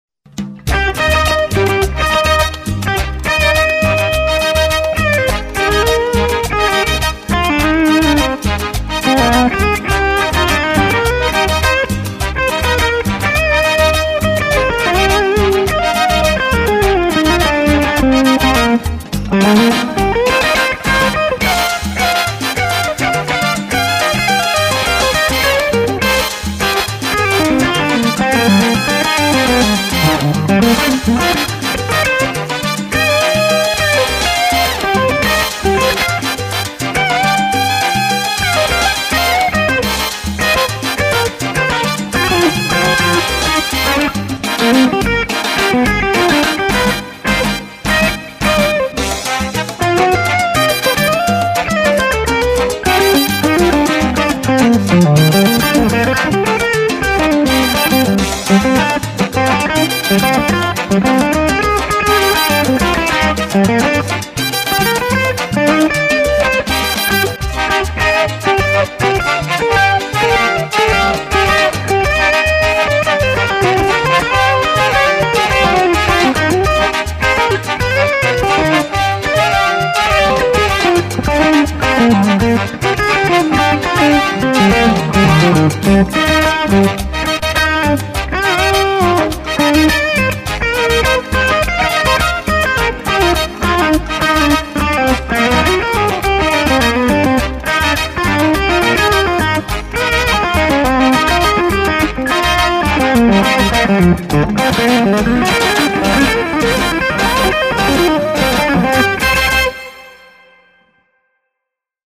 Tone came out fat and juicy. 6L6 tubes in amp....
Very smooth.
The attack on the notes sounds SO fun to play with.
Just great, full, rich tone.